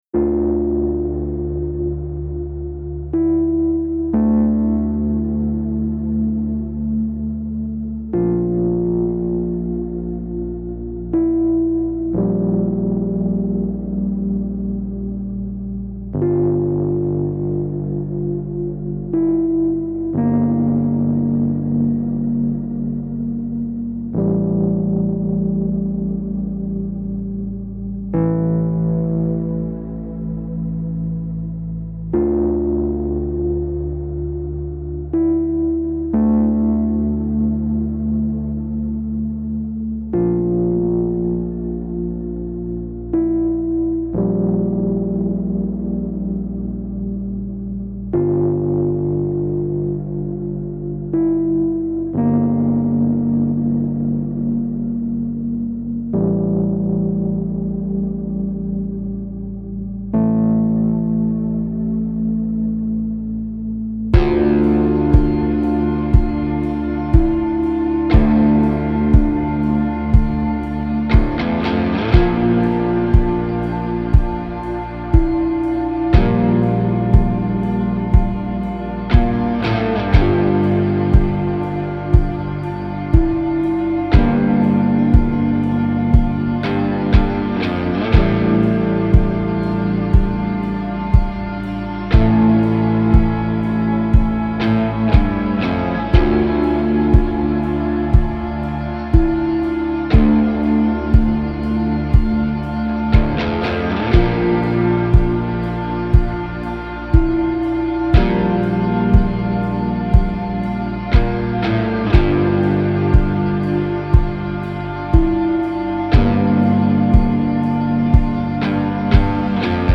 عمیق و تامل برانگیز
گیتار الکترونیک